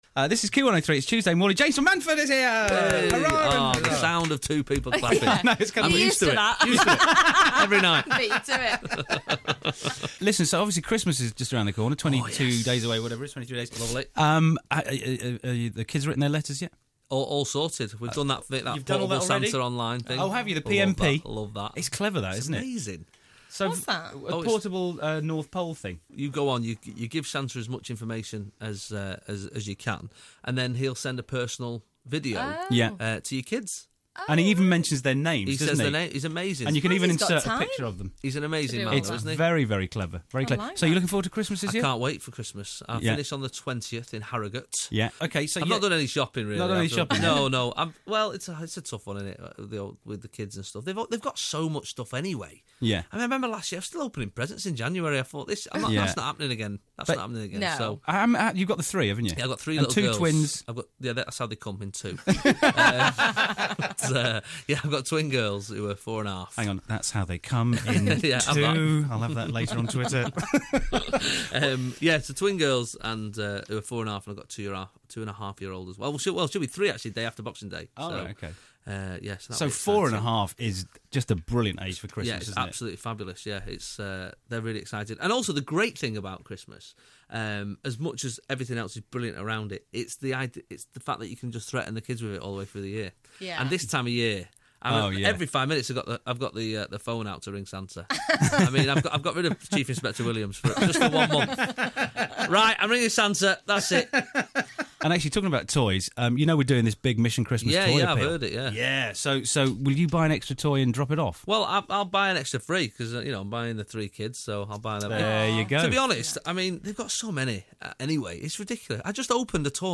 Hear the second part of the Manchester comedian's chatting to Key 103 Breakfast.